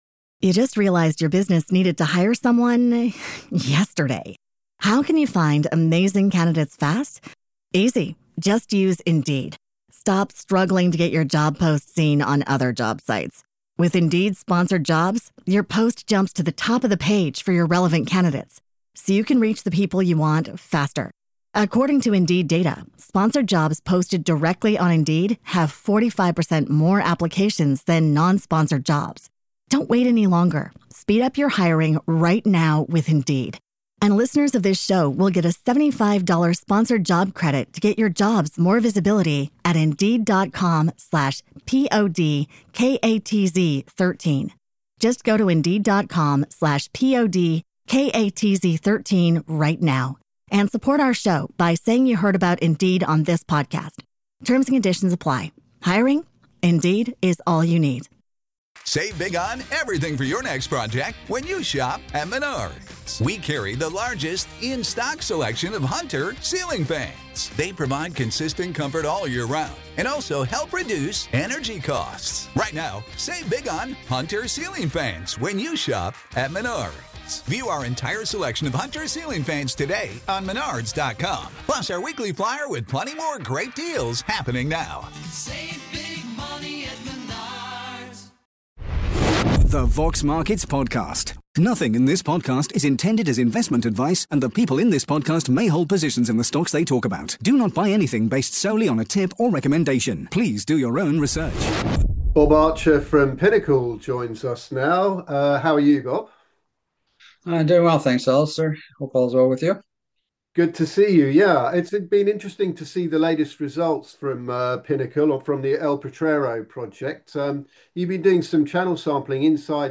Q&A with Pinnacle Silver and Gold